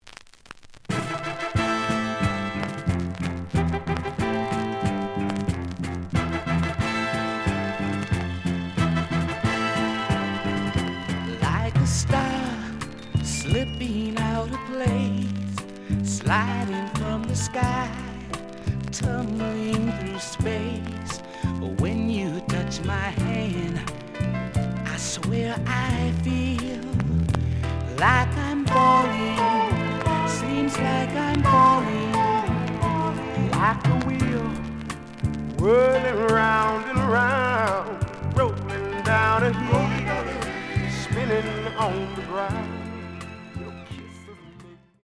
The audio sample is recorded from the actual item.
●Genre: Soul, 70's Soul
Some click noise on both sides due to scratches.